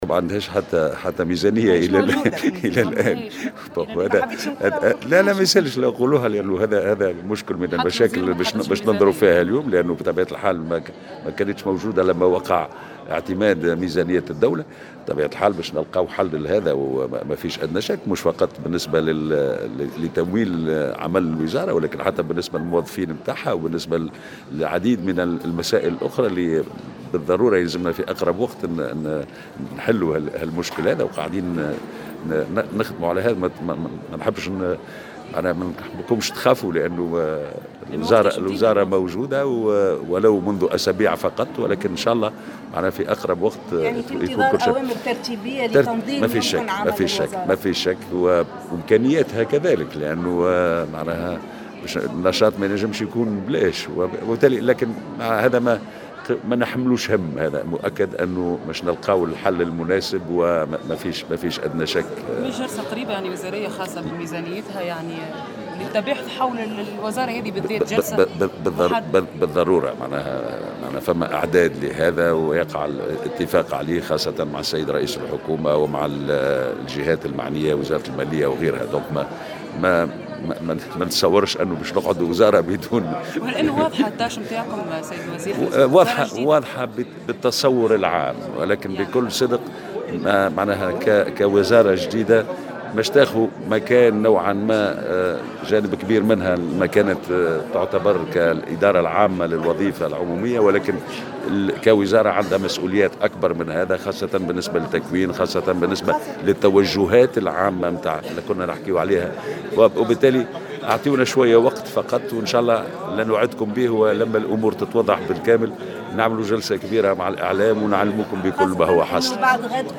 وأوضح في تصريح لمراسل "الجوهرة أف أم" أن هذه الوزارة تم احداثها بعد ضبط ميزانية الدولة معربا عن امله في ايجاد حل لهذه الوضعية الاستثنائية في أقرب وقت.